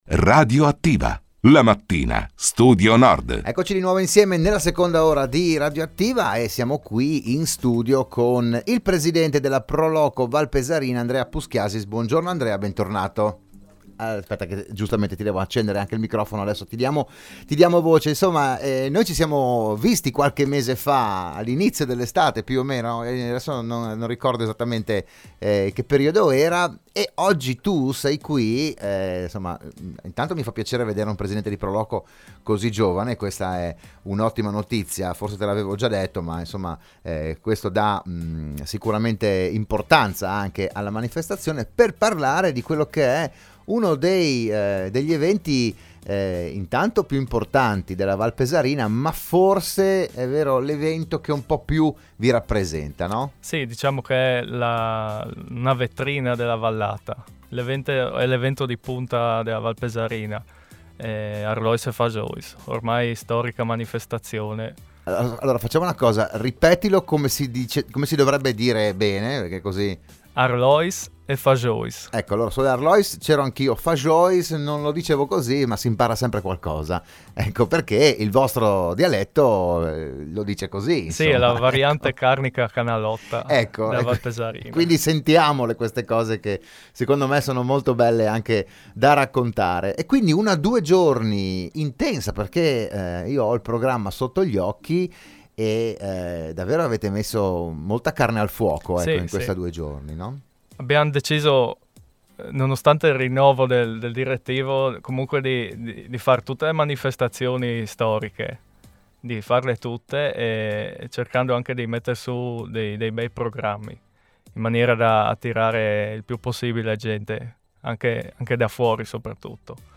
Proponiamo il podcast dell’intervento odierno